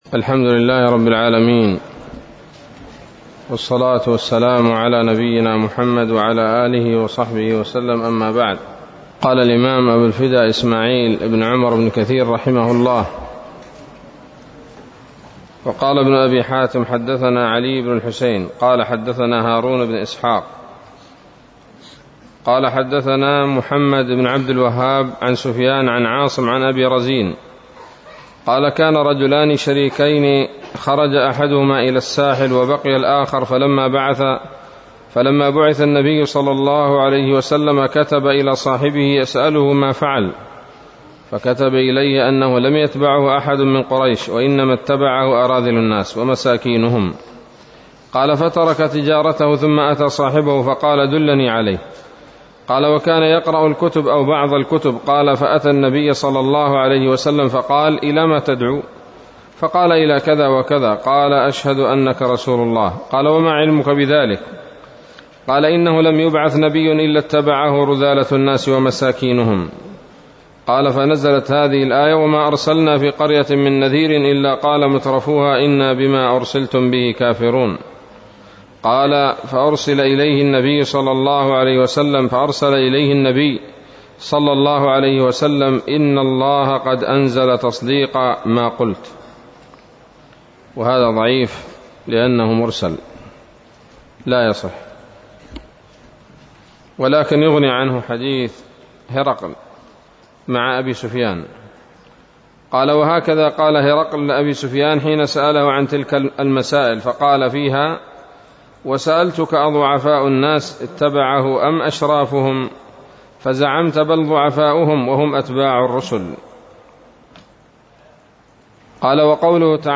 الدرس الثالث عشر من سورة سبأ من تفسير ابن كثير رحمه الله تعالى